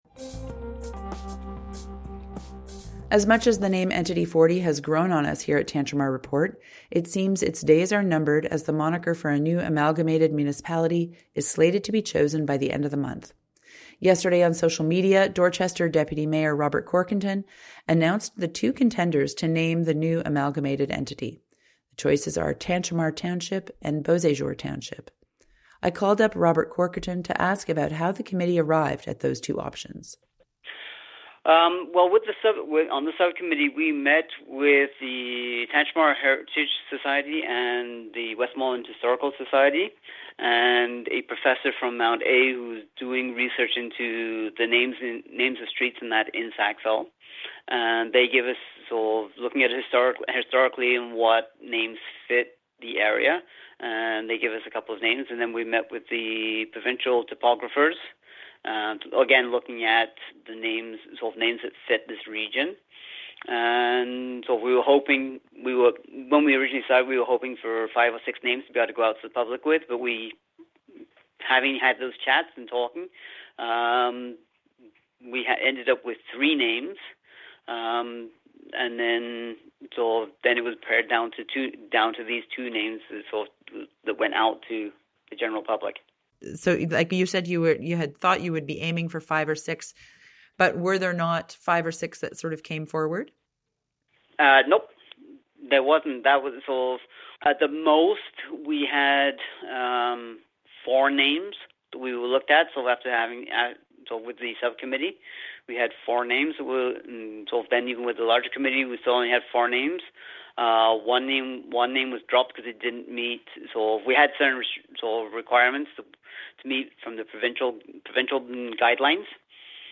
CHMA called up Dorchester Deputy Mayor Robert Corkerton to talk about the choices and why they were made, as well as which names didn't quite make the cut.